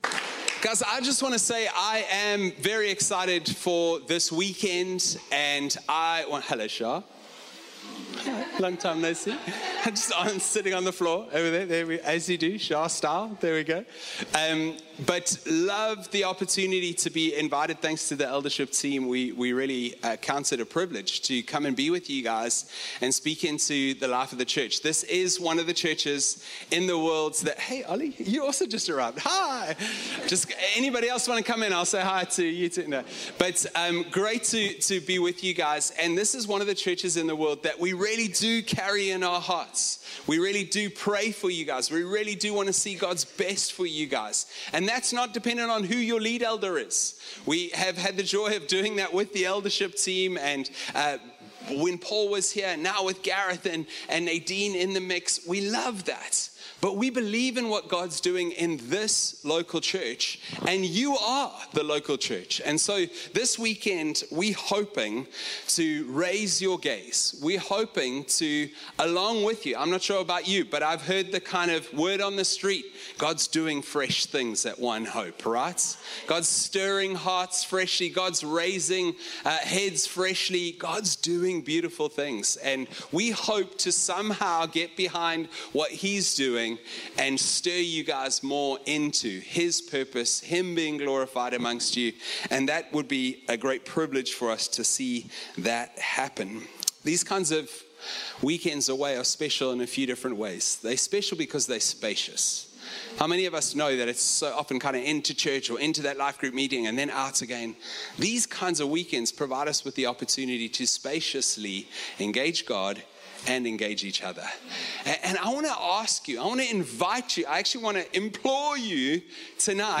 One-Hope-Family-Camp-Sermon-1-2025.mp3